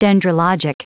Prononciation du mot dendrologic en anglais (fichier audio)
dendrologic.wav